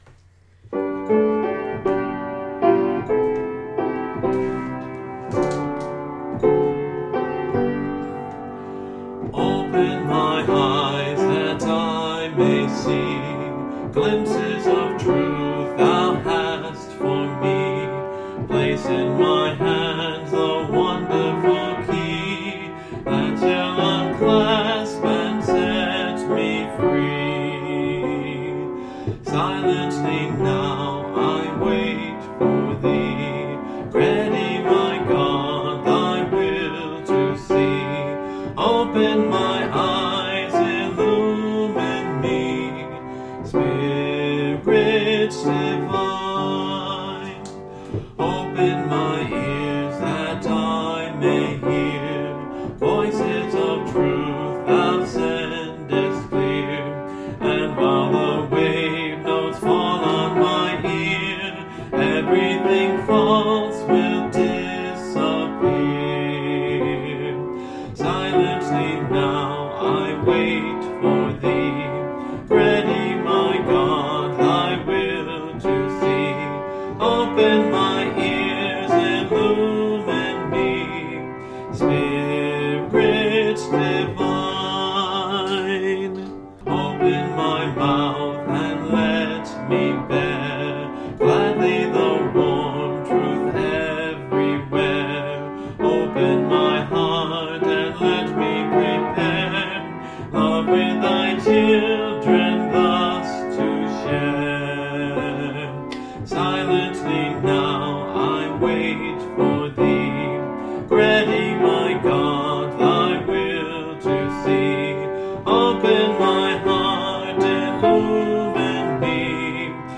(Part of a series singing through the hymnbook I grew up with: Great Hymns of the Faith)